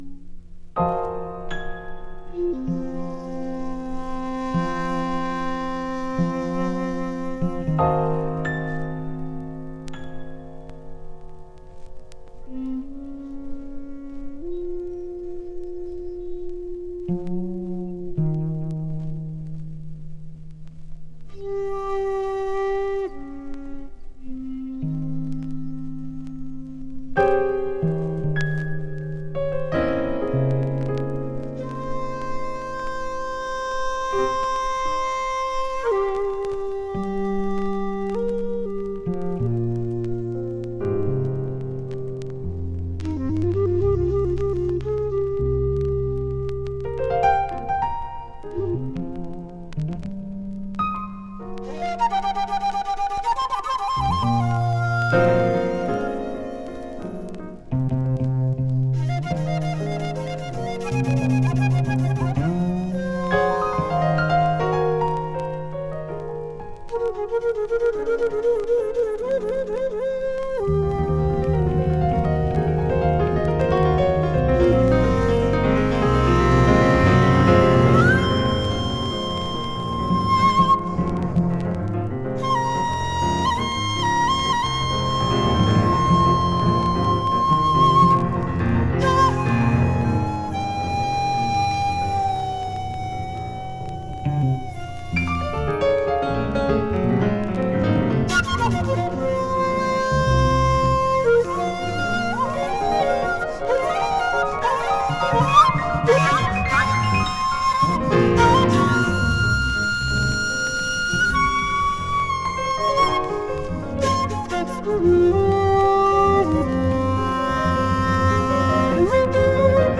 JAPANESE CONTEMPORARY & SPIRITUAL JAZZ!
one of Japan's leading shakuhachi players
piano, a spiritual and contemporary Japanese jazz number
There is a slight dust noise part.